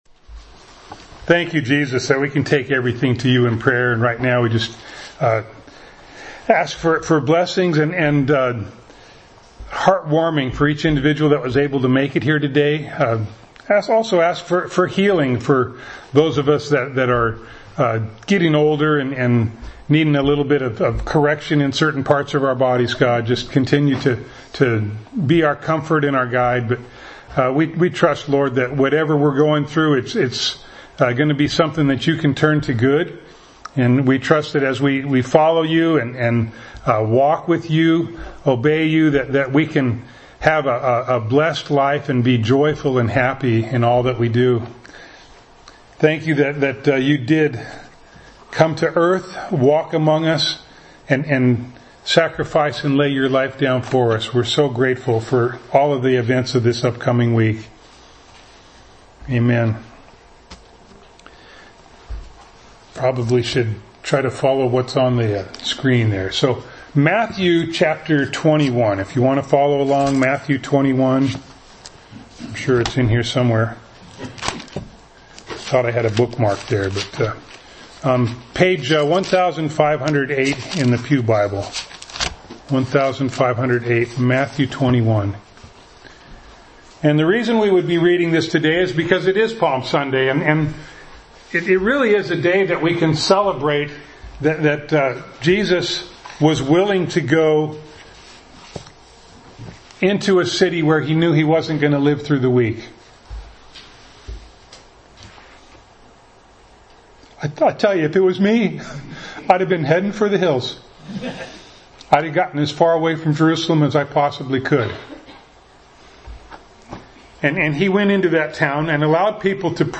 James 4:4-6 Service Type: Sunday Morning Bible Text